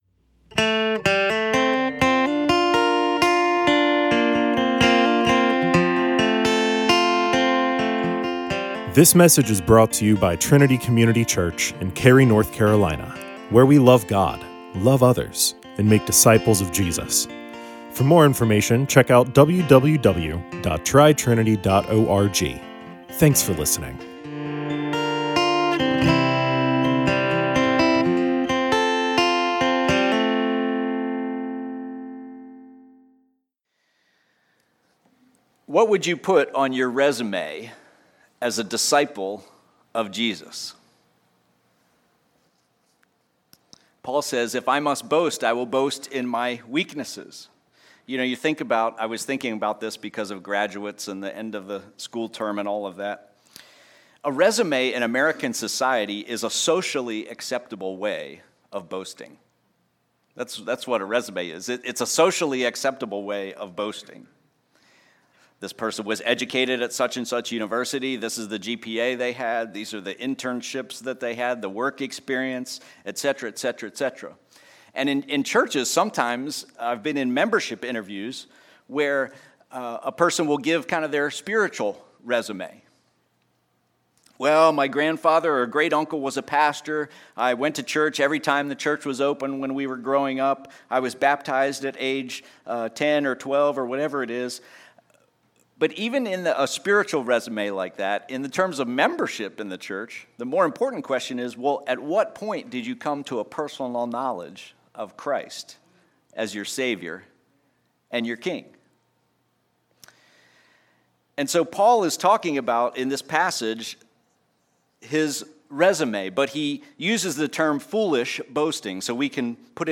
To become more and more like Jesus, God shows us our weakness so that we can depend on Him. Message from II Corinthians 11:16-33.